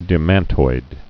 (dĭ-măntoid)